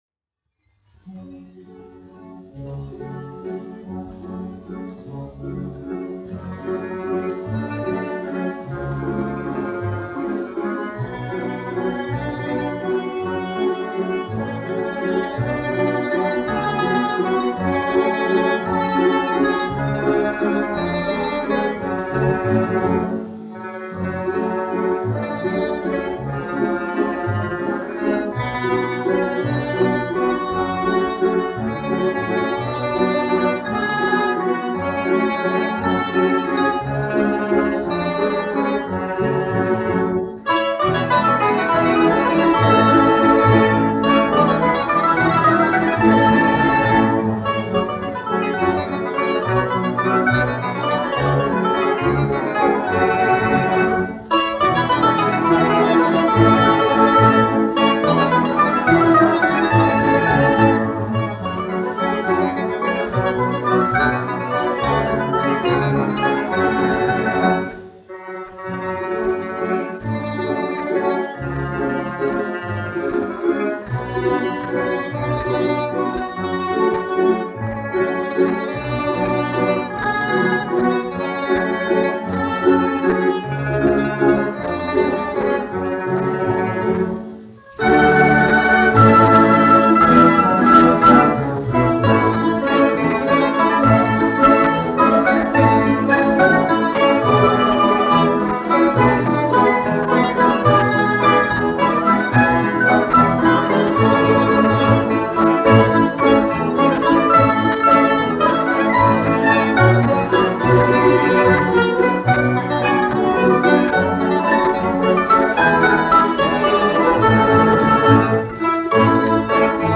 1 skpl. : analogs, 78 apgr/min, mono ; 25 cm
Balalaiku orķestra mūzika